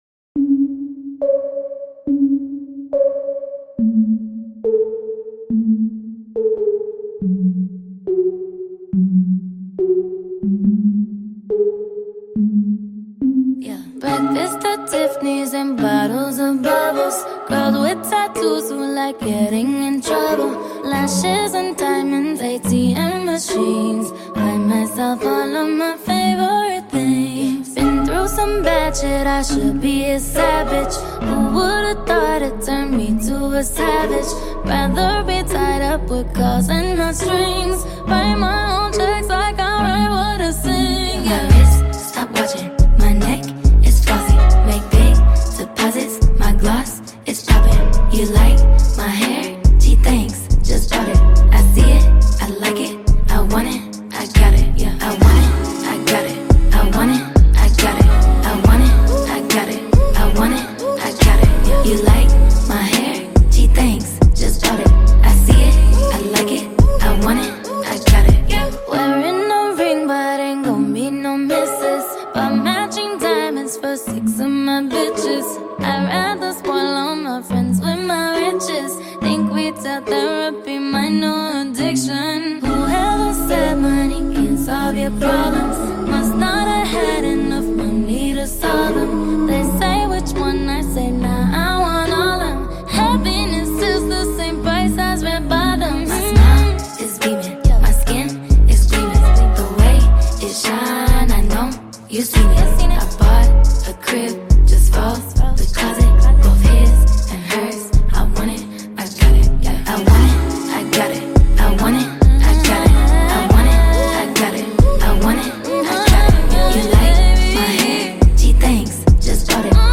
The international female musical artist